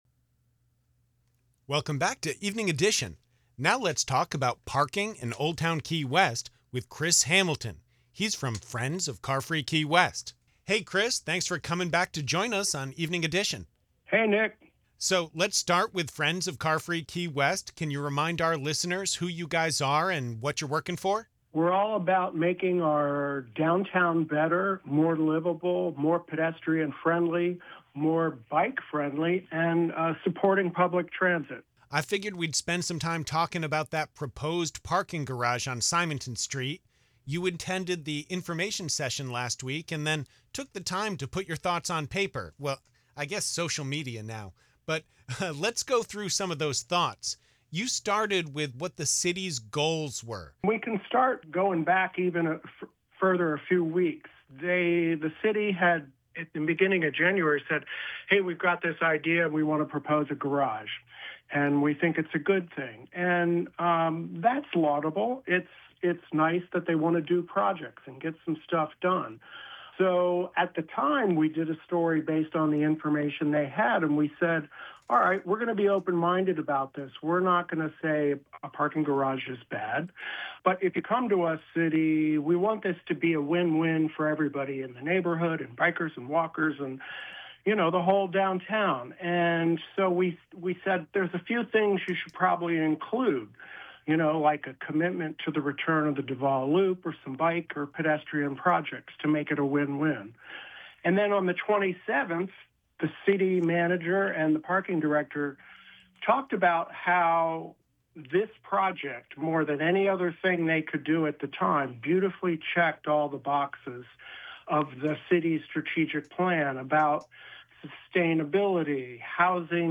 Interview
U.S. Radio 1 Evening Edition’s